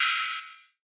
DDW 8 Bit Open Hat.wav